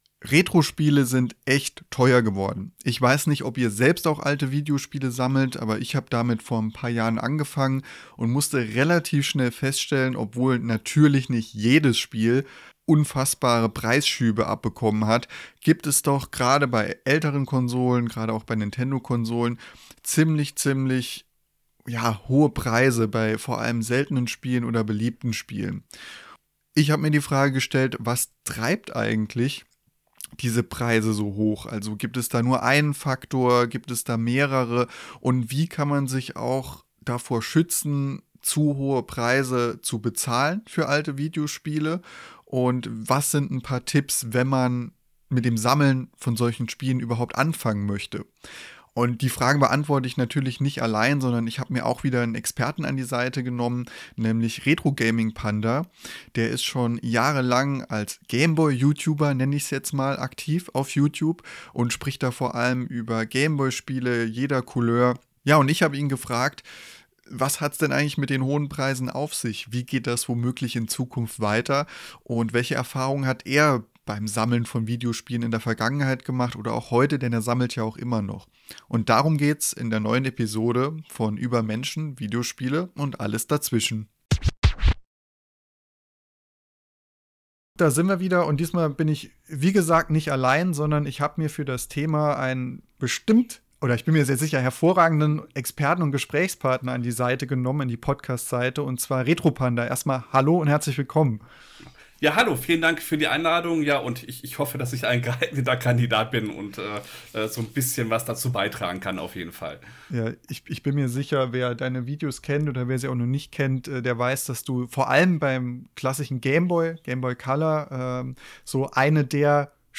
Im Interview: